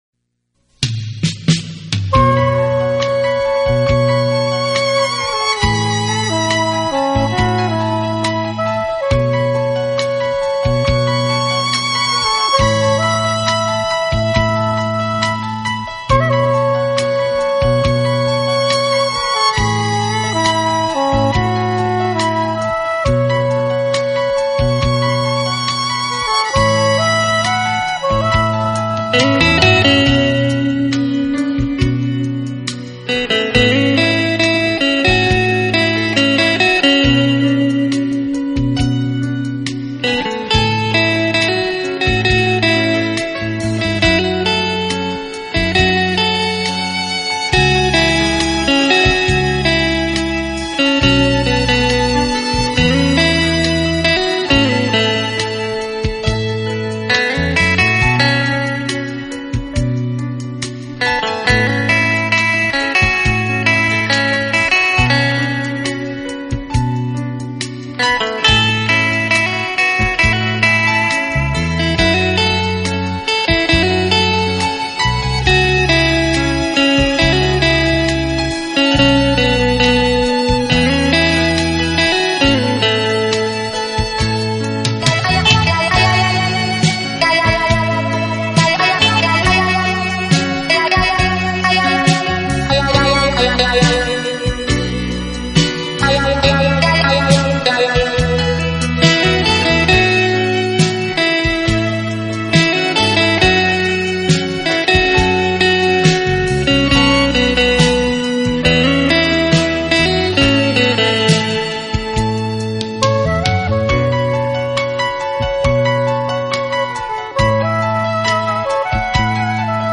专辑音色清脆动人且温馨旖丽，不禁展示了精彩绝伦的空间感，而且带出吉他
音箱共鸣声的无限通透。
用吉他的清脆表现大师音乐的干净、深度和静谧美丽得让人心碎的旋律。